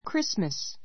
Chris t mas 小 krísməs ク リ ス マ ス （ ⦣ t は発音しない） 名詞 ❶ クリスマス , キリスト降誕祭 ⦣ Xmas と略す.